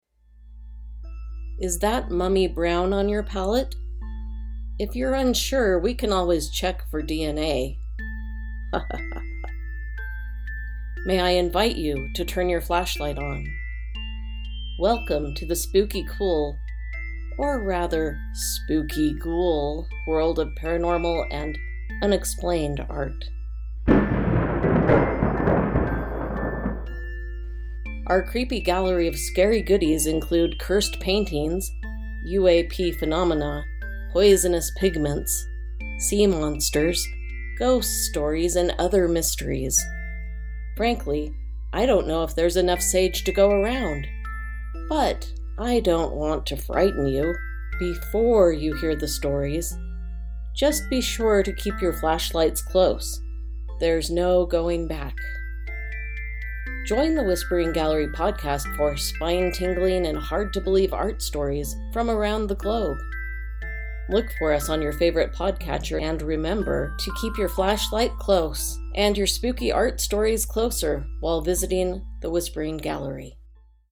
Sound Effects and Music from Free Sound: Thunder: